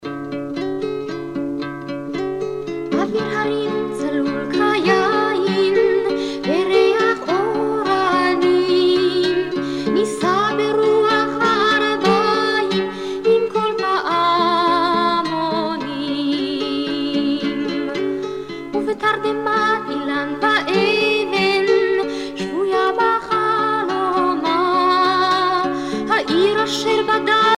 Chants de retour et d'espoir
Pièce musicale éditée